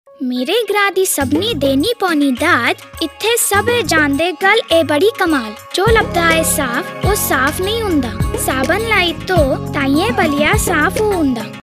This public service announcement is one in a series for a two year campaign on sanitation, hygiene and judicious use of water.